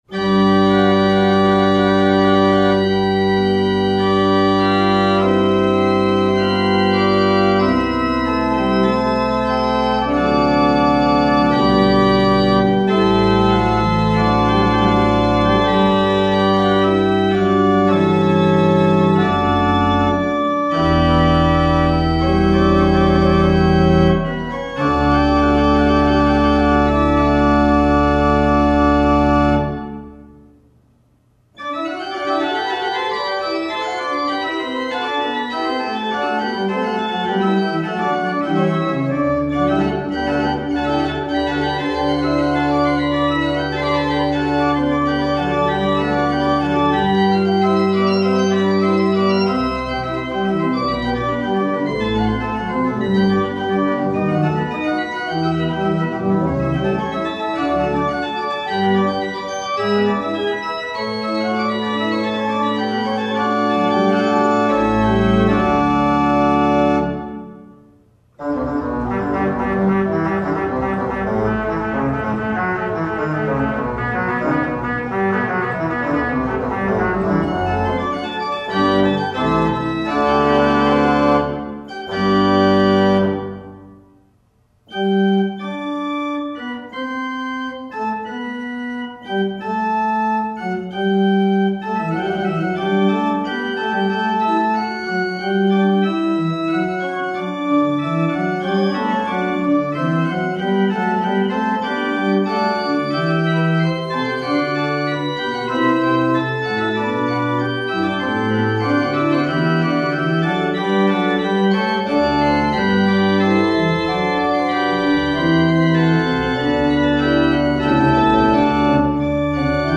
Short Baroque organ works